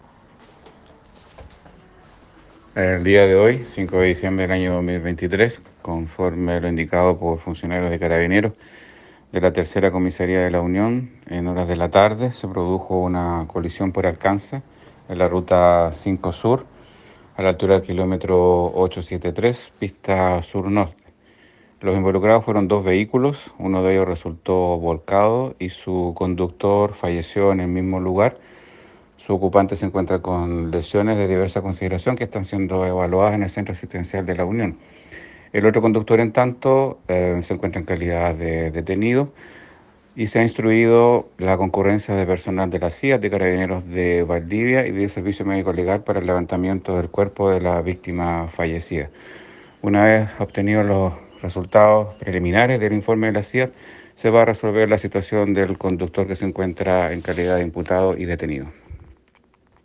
fiscal jefe de La Unión, Raúl Suárez.  Sobre la investigación que dirige la Fiscalía por el fallecimiento del conductor de un vehículo que chocó contra otro automóvil esta tarde.